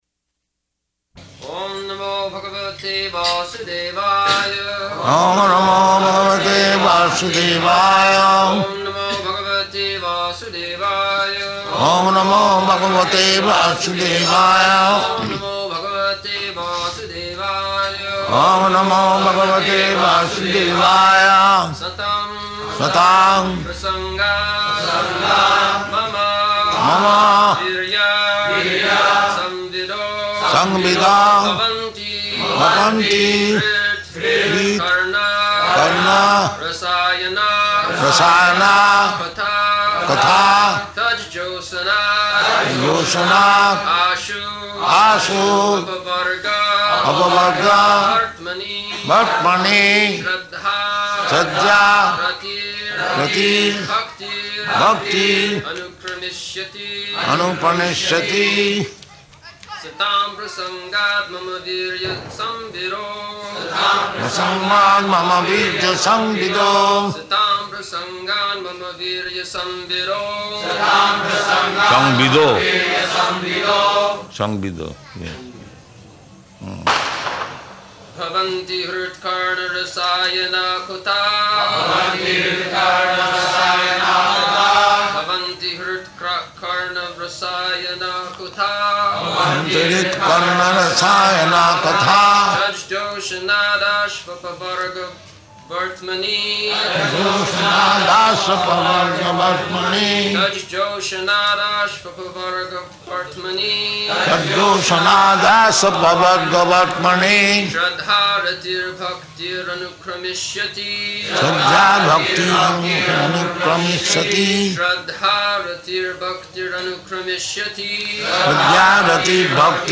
November 25th 1974 Location: Bombay Audio file
[noise of fireworks in background]
[child talking] [aside:] That child...